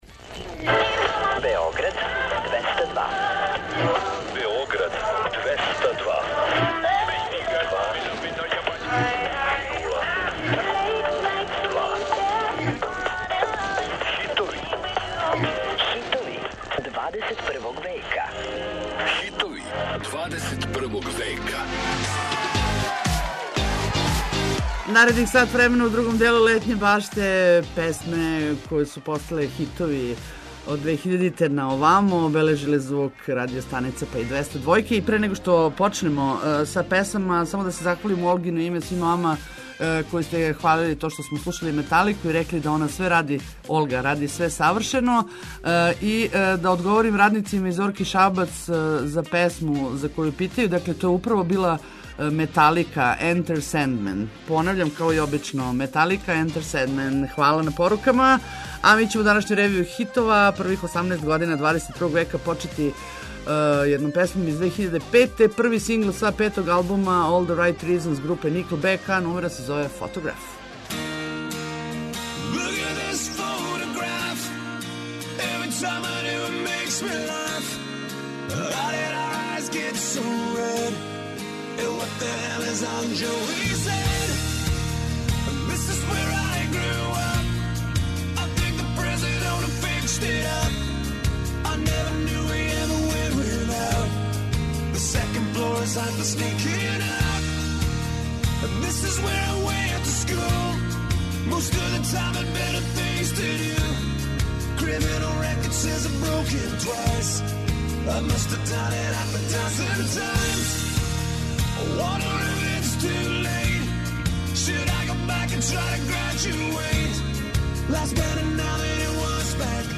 Очекују вас највећи хитови 21. века!